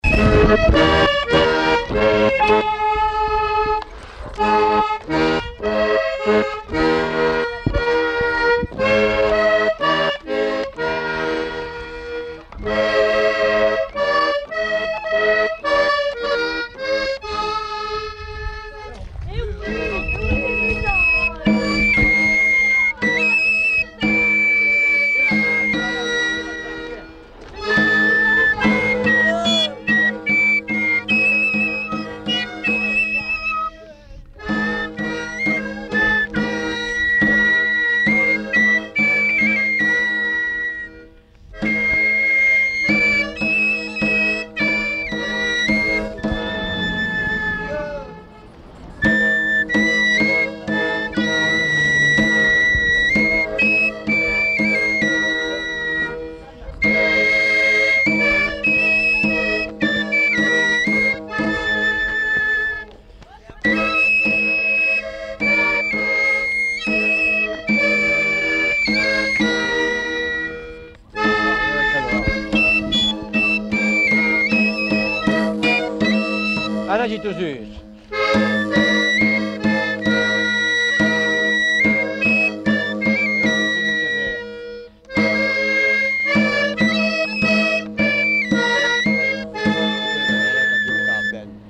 Aire culturelle : Béarn
Lieu : Castet
Genre : morceau instrumental
Instrument de musique : flûte à trois trous ; tambourin à cordes ; accordéon diatonique